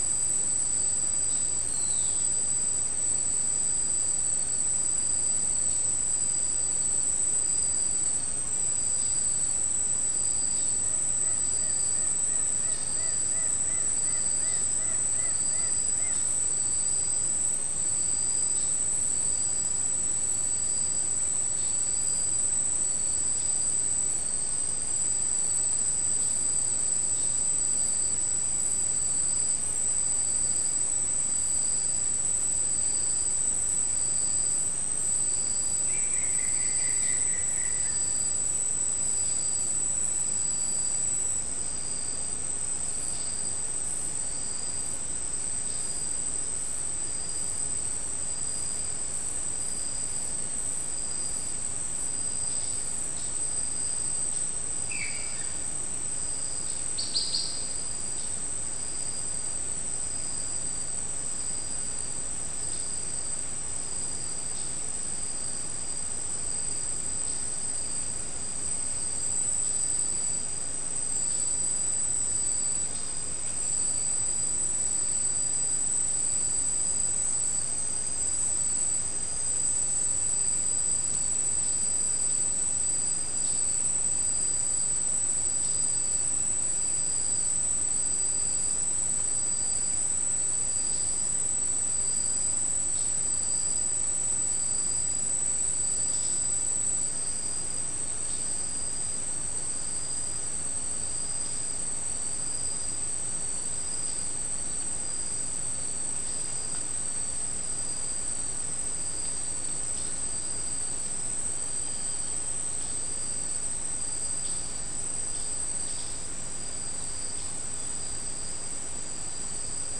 Soundscape
South America: Guyana: Sandstone: 3
Recorder: SM3